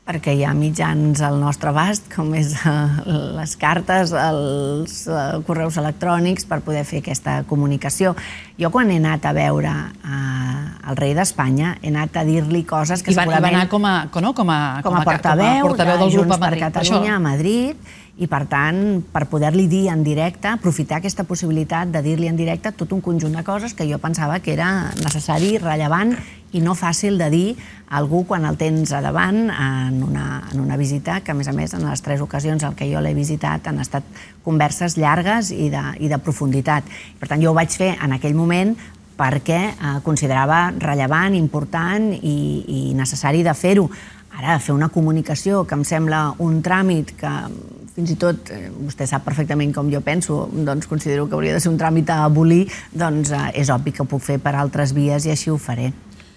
En una entrevista a ‘Els Matins de TV3’, ha argumentat que hi ha altres mitjans per fer-ho i que es tracta d’un “tràmit” que considera que s’hauria d’abolir.
Podeu escoltar aquí un fragment de l’entrevista en el qual parla del tràmit d’haver de comunicar la investidura al rei espanyol: